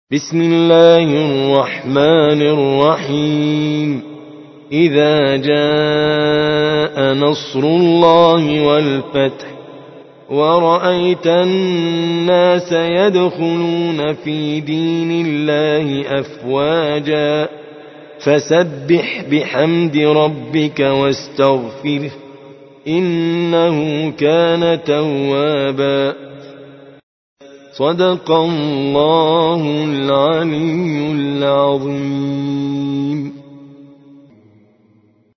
110. سورة النصر / القارئ